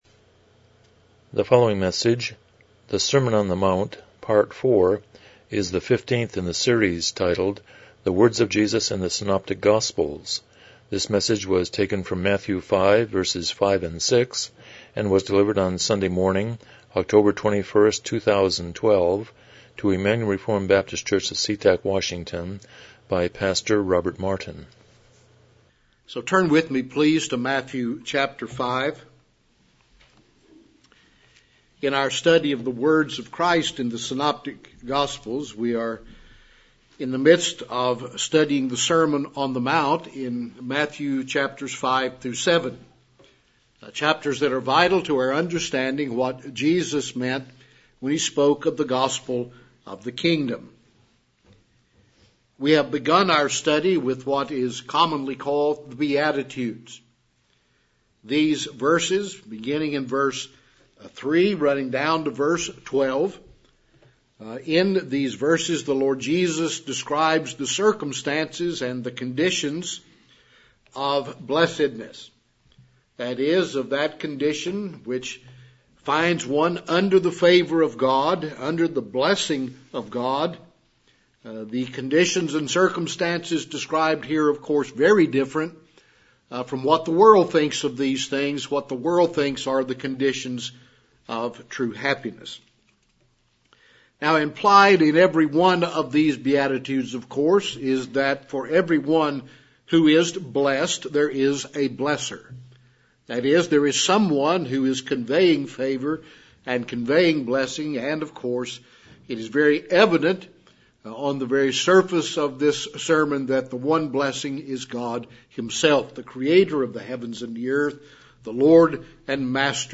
Passage: Matthew 5:5-6 Service Type: Morning Worship